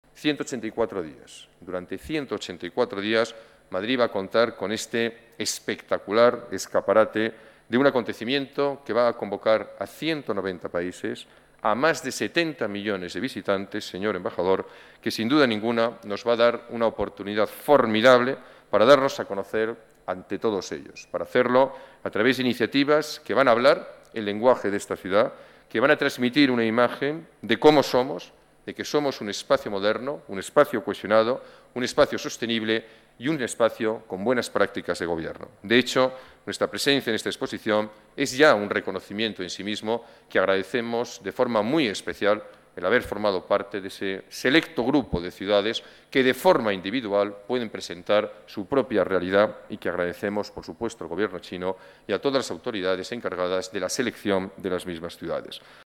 Nueva ventana:Declaraciones de Gallardón: 184 días en la expo de Shanghai